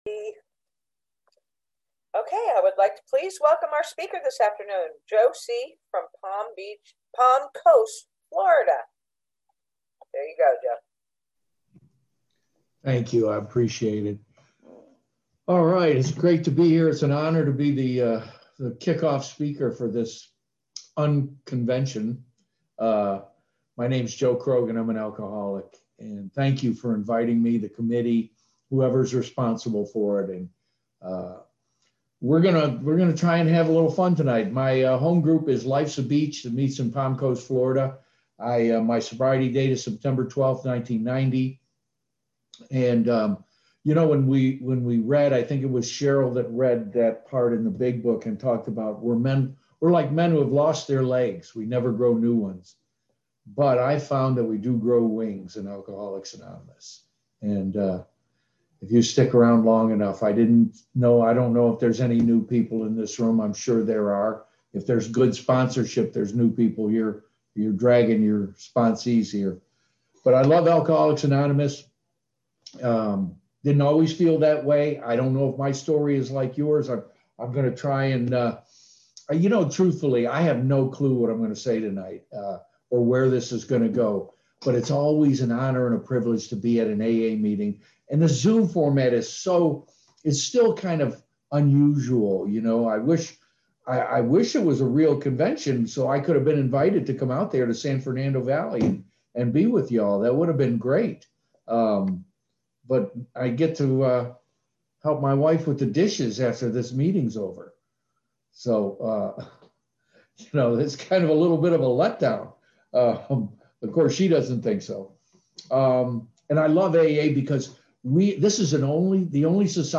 46th San Fernando Valley Alcoholics Anonymous UnConventional